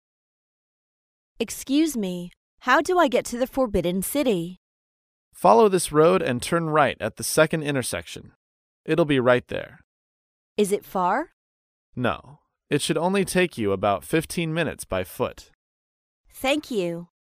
在线英语听力室高频英语口语对话 第19期:找名胜古迹的听力文件下载,《高频英语口语对话》栏目包含了日常生活中经常使用的英语情景对话，是学习英语口语，能够帮助英语爱好者在听英语对话的过程中，积累英语口语习语知识，提高英语听说水平，并通过栏目中的中英文字幕和音频MP3文件，提高英语语感。